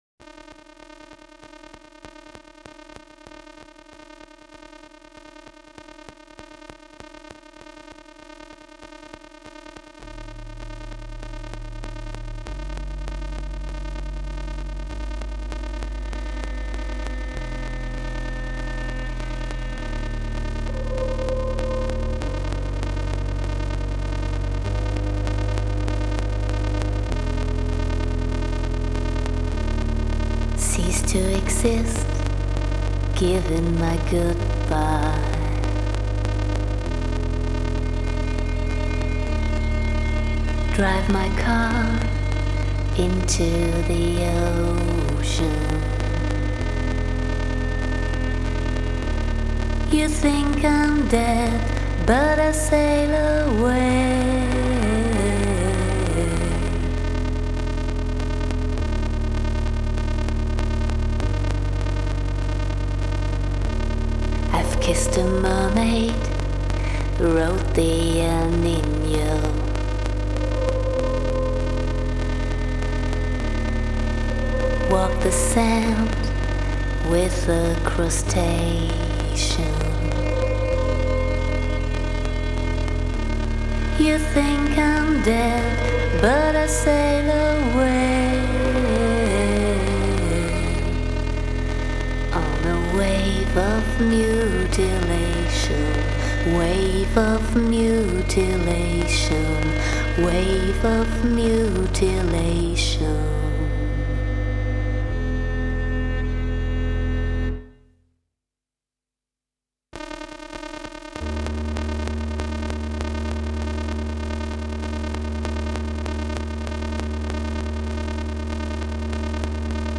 Musik, Sounds, Cello-Bearbeitung: